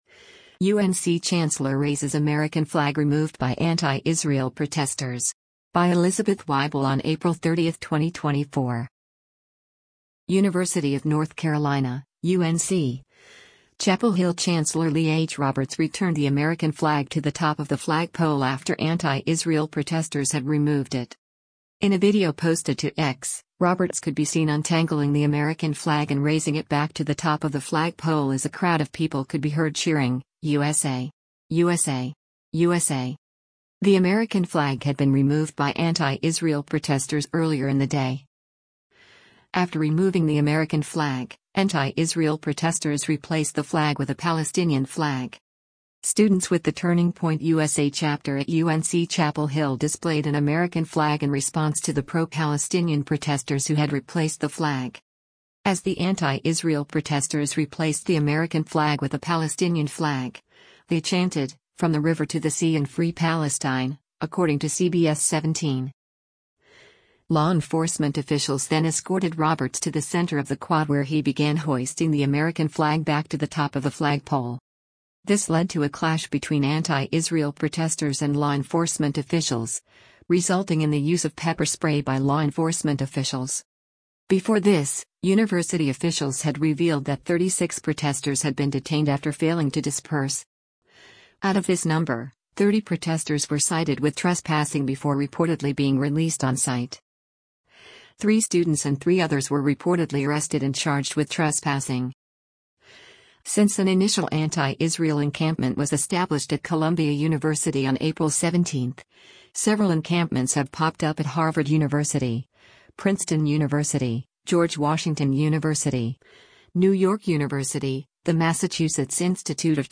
In a video posted to X, Roberts could be seen untangling the American flag and raising it back to the top of the flag pole as a crowd of people could be heard cheering, “USA! USA! USA!”